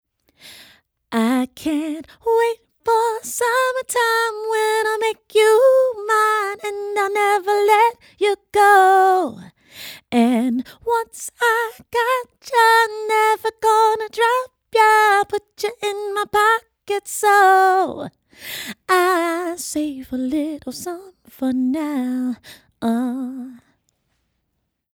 Female Vocals - LCT 440 PURE
lewitt_lct-440-pure-female-vocals.mp3